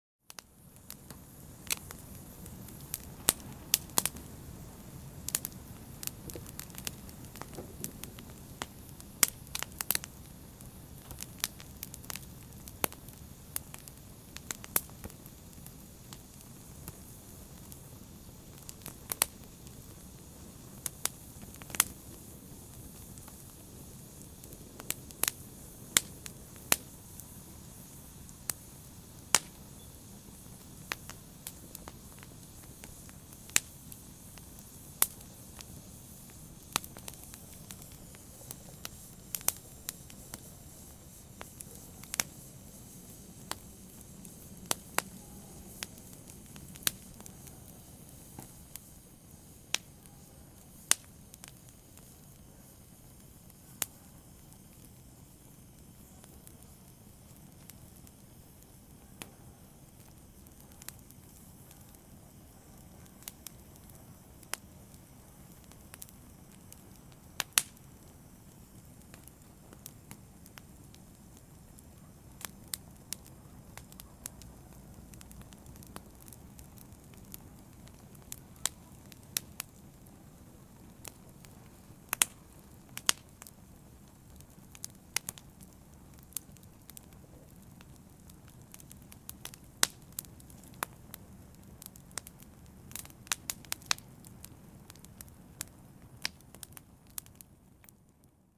fire-3.ogg